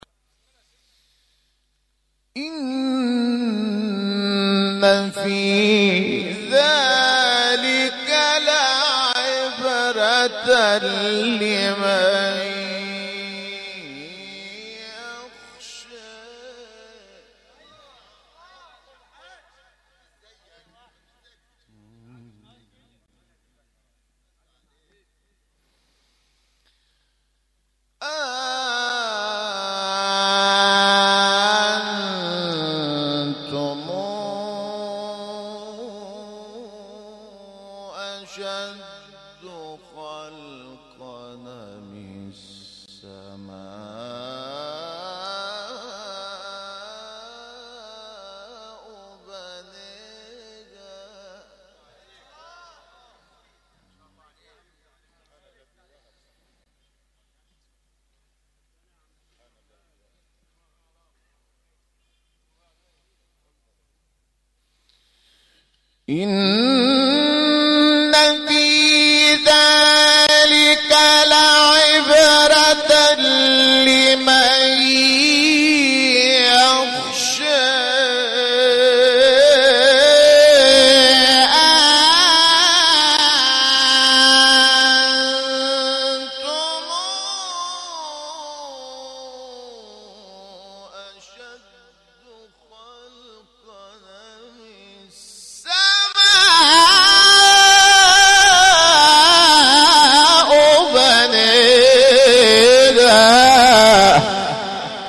مقام: زنکران (ترکیب حجاز و چهارگاه)